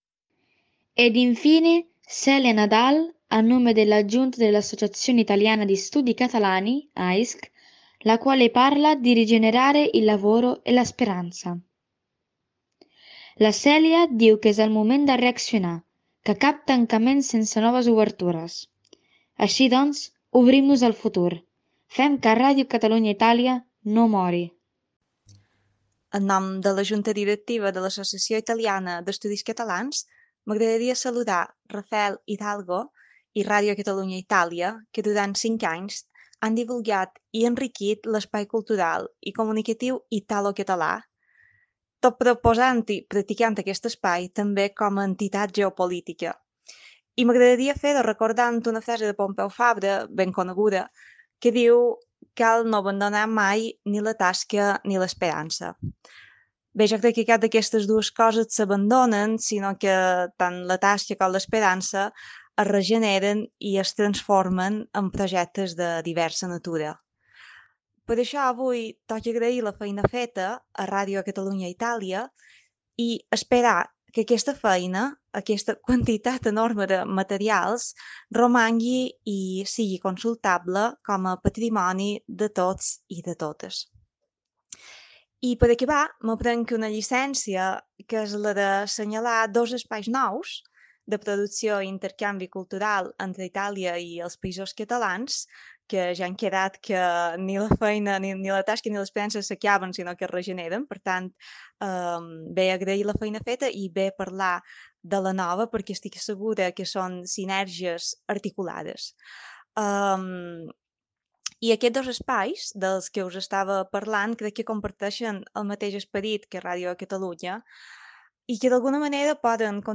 Últim programa de la ràdio.
Cultural